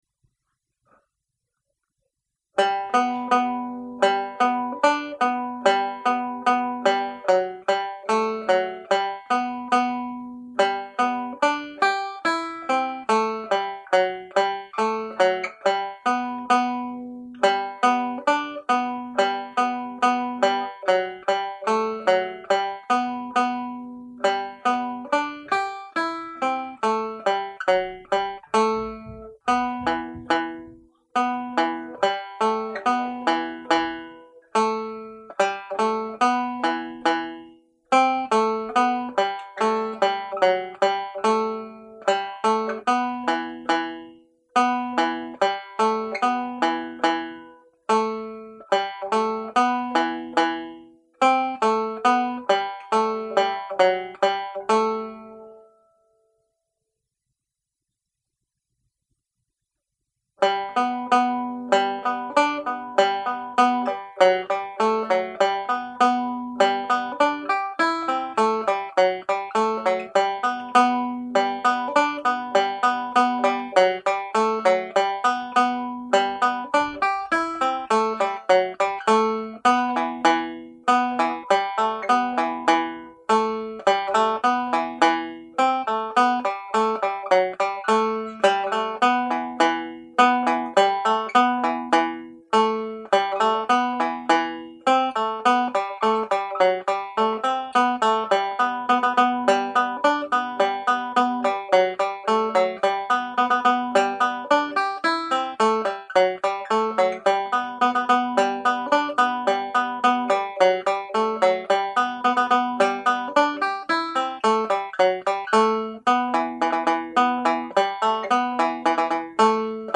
Reel (G Major)